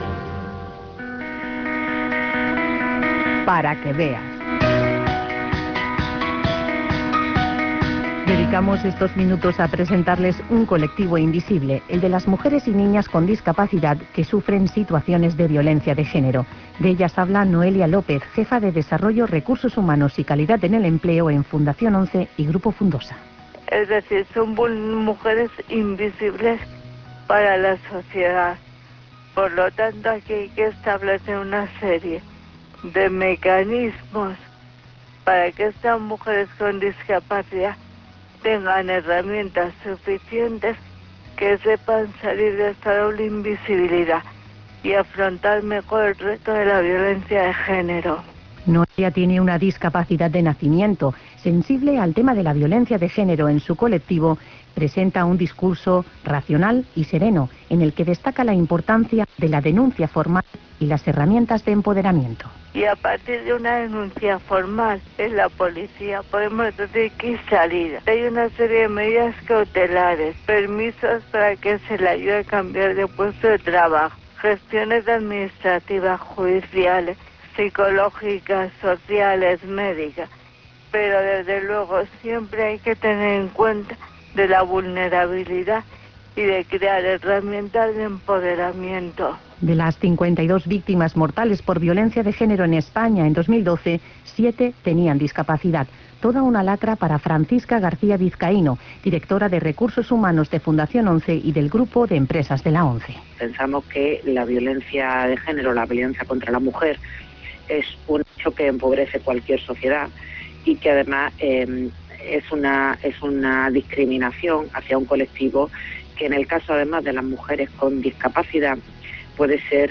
Reportaje sobre las mujeres y niñas con discapacidad que sufren situaciones de violencia de género. De las 52 víctimas mortales por violencia de género en España en 2012, 7 tenían discapacidad.